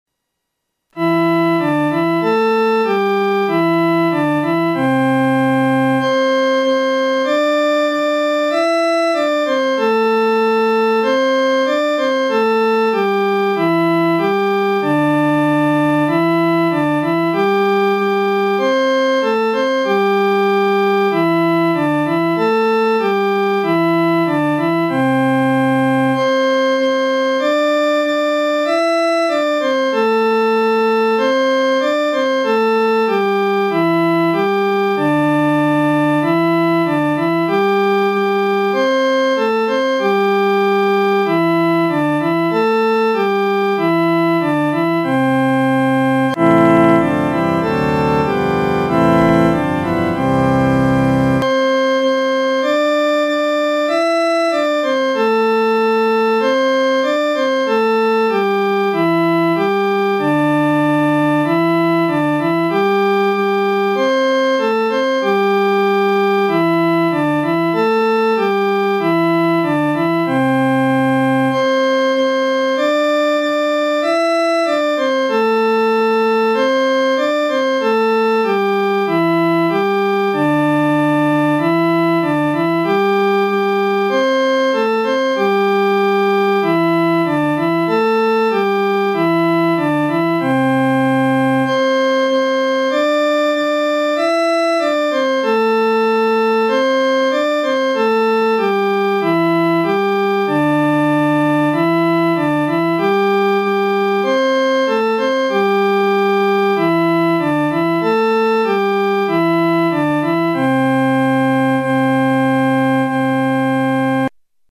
伴奏
女高
所用曲谱是在河北省一带于解放前所流行的民歌，曾被配上多种歌词。